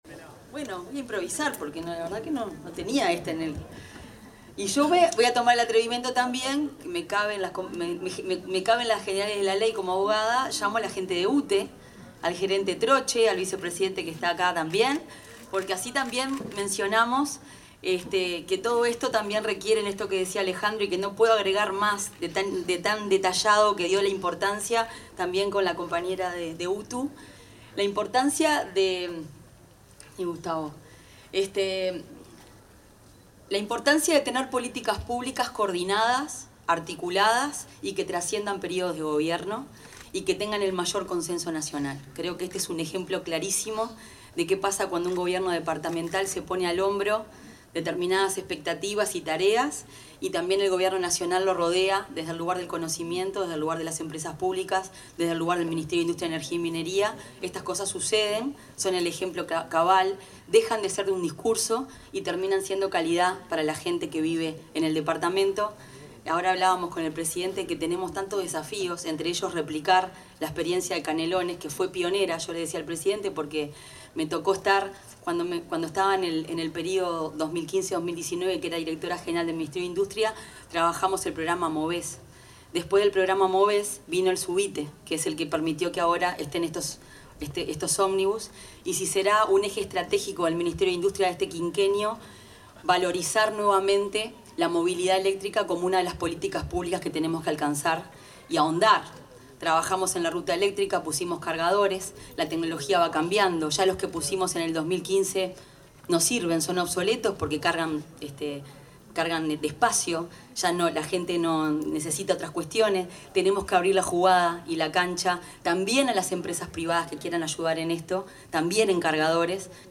Palabras de la ministra de Industria, Energía y Minería, Fernanda Cardona
Durante la entrega de ómnibus eléctricos en Las Piedras, se expresó la ministra de Industria, Energía y Minería, Fernanda Cardona.